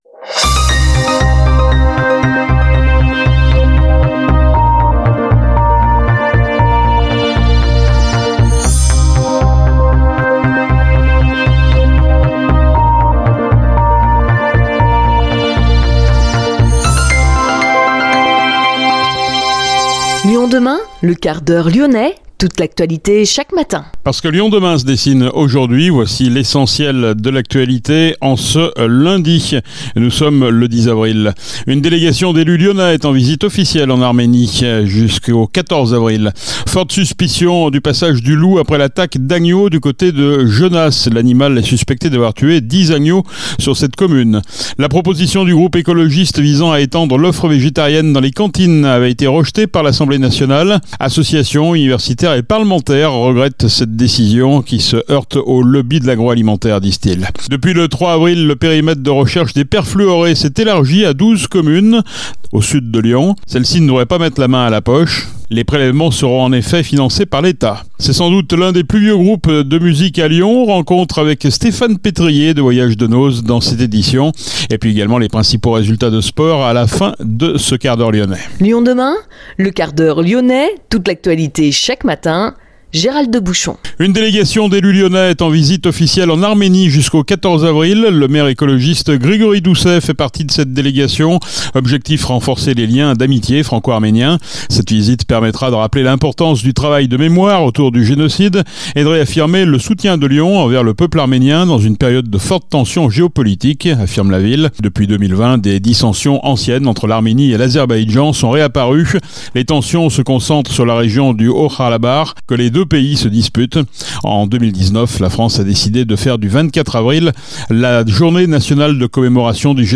Ecoutez l’interview dans ce 1/4H LYONNAIS.